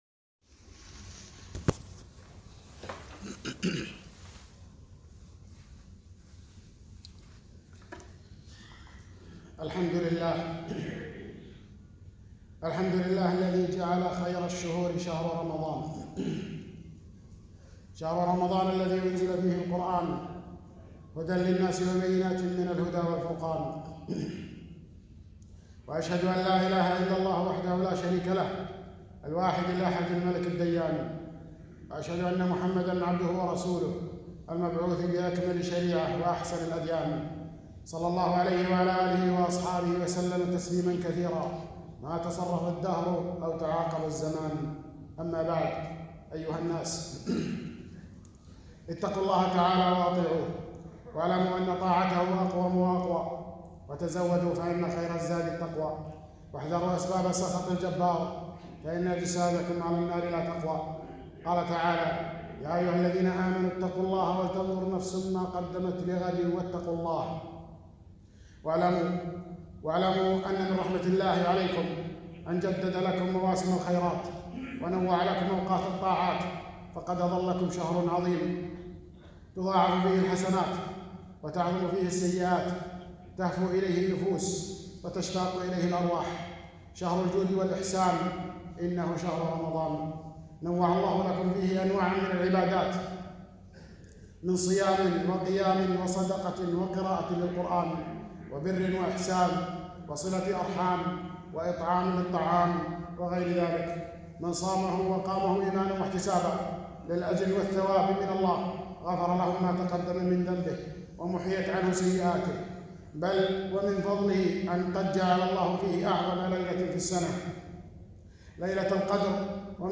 خطبة - بين يدي رمضان